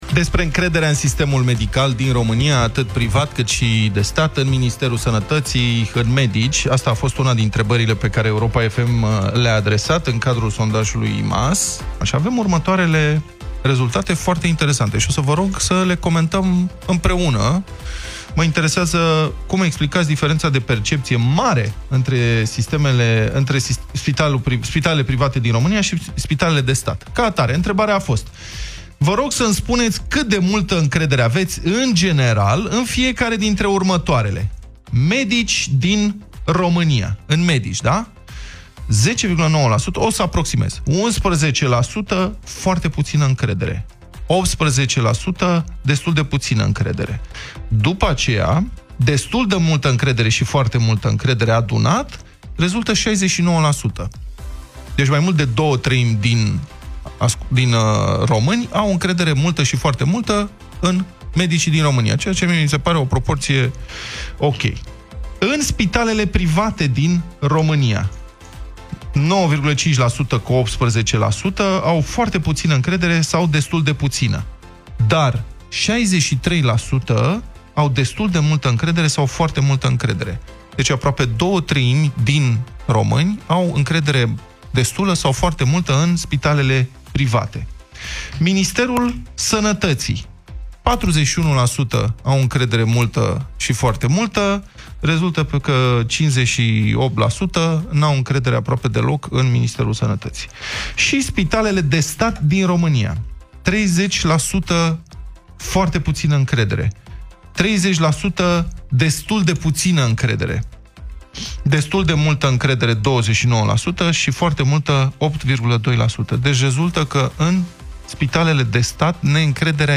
Dezbaterea dimineții: Românii au mai multă încredere în spitalele private (AUDIO)